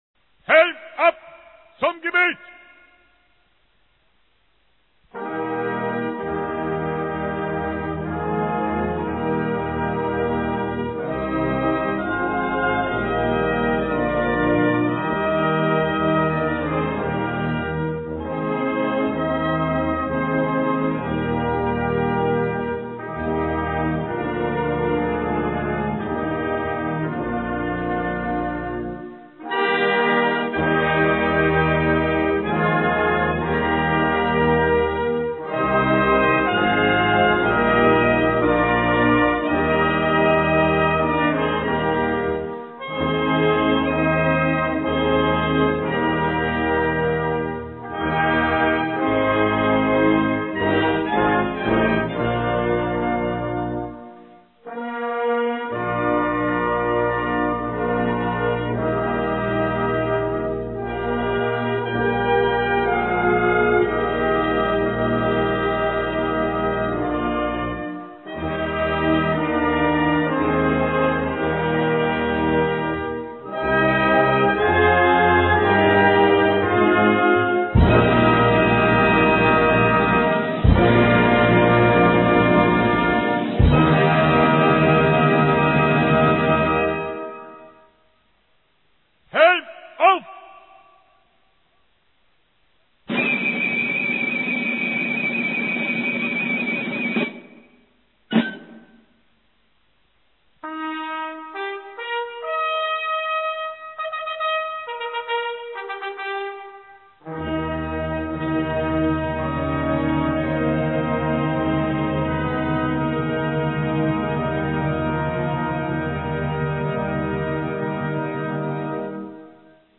Der "Große Zapfenstreich" ist eine militärmusikalische Zeremonie, die nur zu besonderen Anlässen ausgeführt wird, wobei im allgemeinen ein Musikkorps sowie eine Kompanie Infanterie und Fackelträger mitwirken.
Locken - Zapfenstreichmarsch - Harmonischer Zapfenstreich der Reiterei (die "3 Posten") - Zeichen zum Gebet - Gebet ("Ich bete an die Macht der Liebe") - Abschlagen nach dem Gebet - Ruf nach dem Gebet (dem Amen in der Liturgie entsprechend) - Nationalhymne